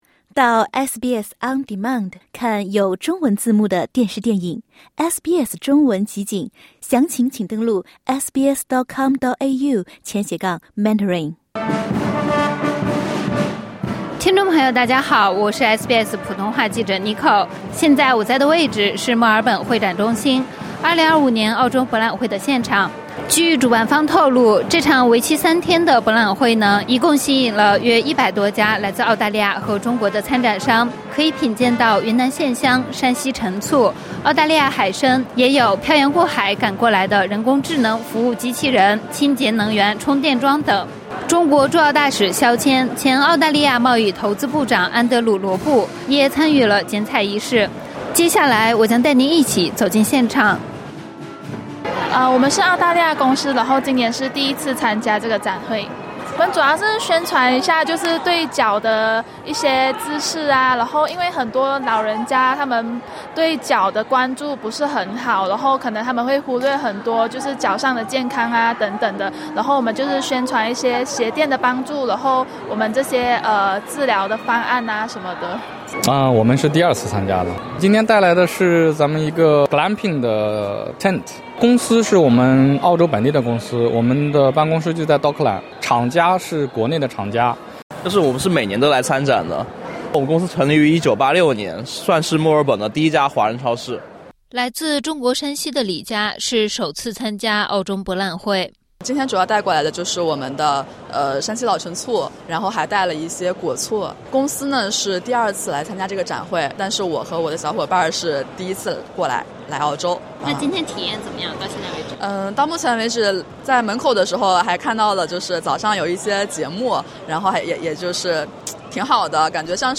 SBS普通话记者在现场采访了参展商和访客。